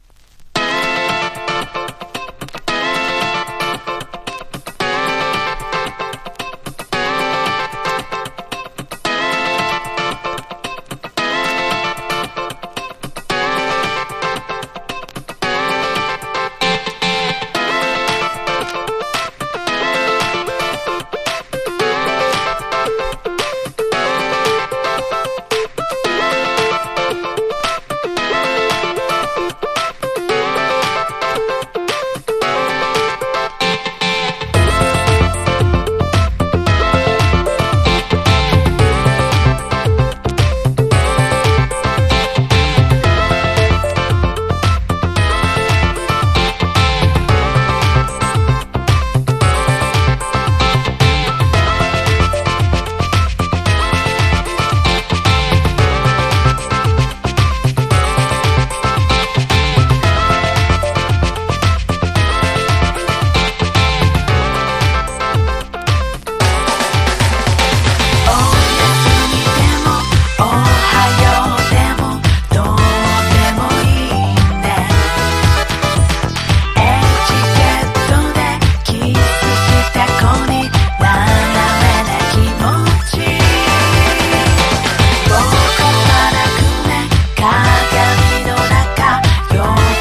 # POP# 和モノ